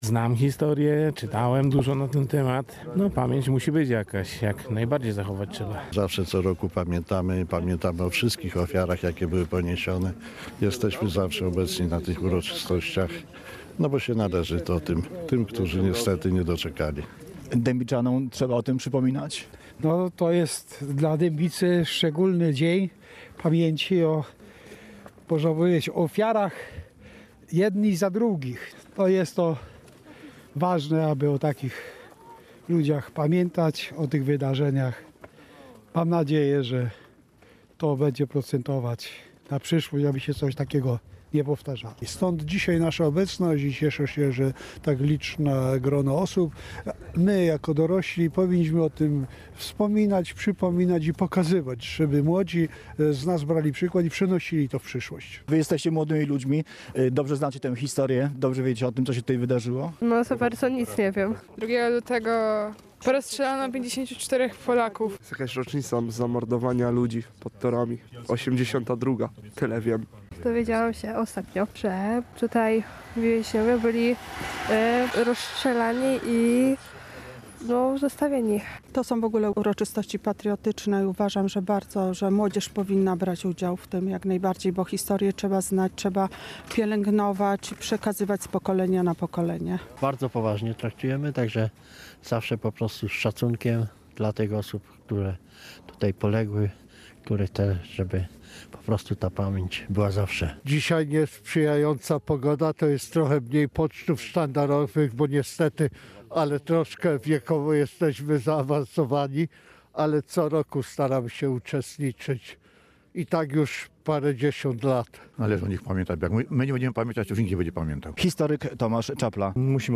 Relacje reporterskie • Mieszkańcy Dębicy, kombatanci, młodzież szkolna i samorządowcy oddali hołd 54 Polakom zamordowanym przez hitlerowców na nasypie kolejowym 82 lata temu.
W uroczystościach upamiętniających tragiczne wydarzenia sprzed 82 lat uczestniczyli mieszkańcy miasta, przedstawiciele środowisk kombatanckich, młodzież szkolna oraz lokalni samorządowcy.